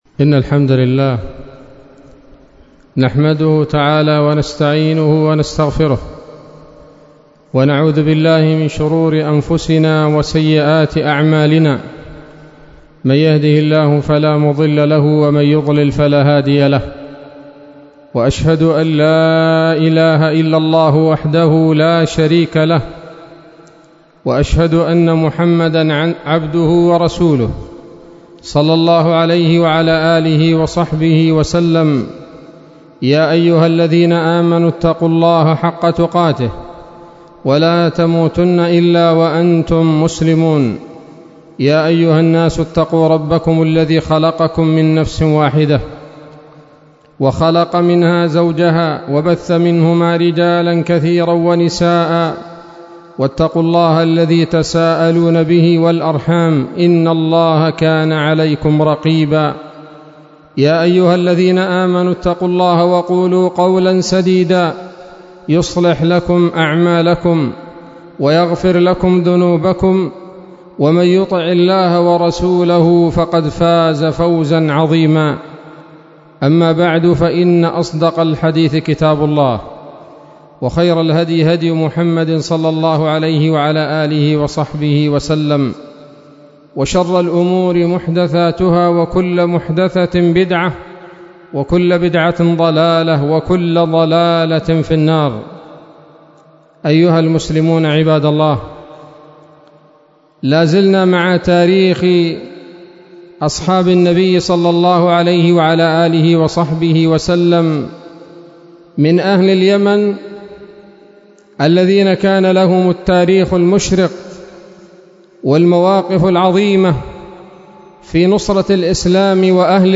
خطبة جمعة بعنوان: (( يمانيون من صحب الرسول ﷺ : جرير بن عبد الله البجلي رضي الله عنه